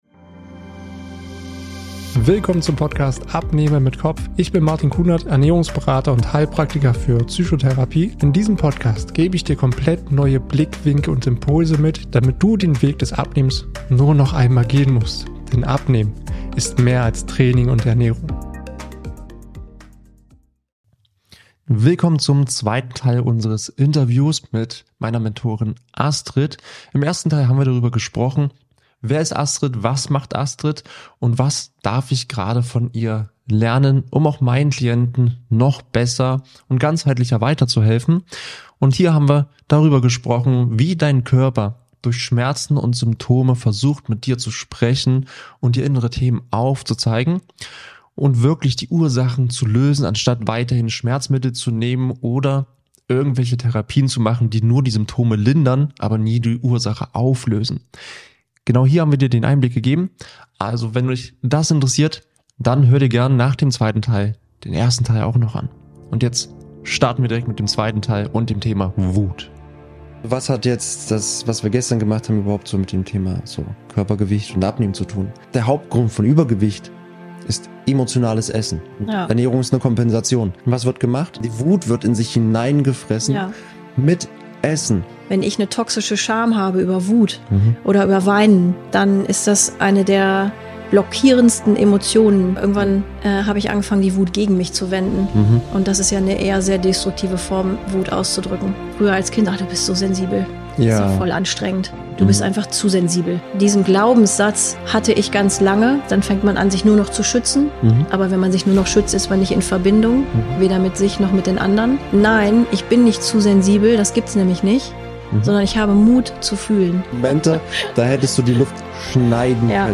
Beschreibung vor 1 Jahr In meinem Interview-Format schaue ich gemeinsam mit meinem Gast weit über den Tellerrand des Abnehmens hinaus.